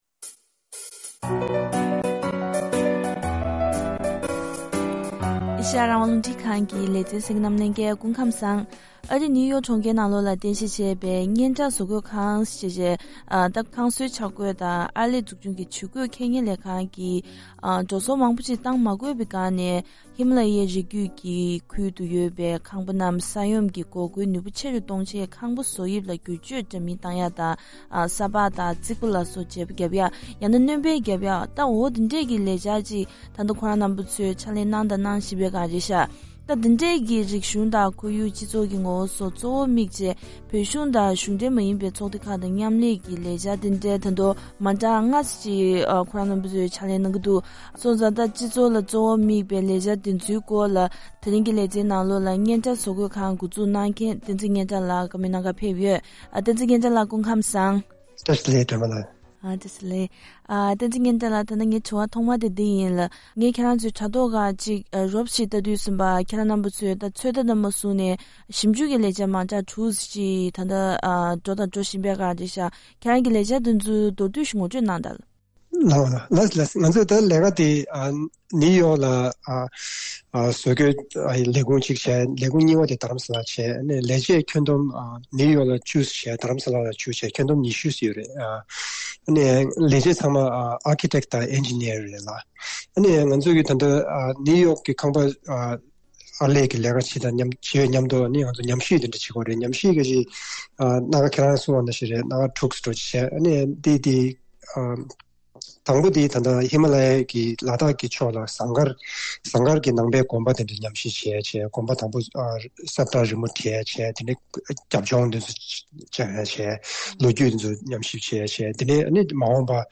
བཀའ་འདྲི་ཞུས་པའི་ལེ་ཚན་ཞིག་གསན་གནང་གི་རེད།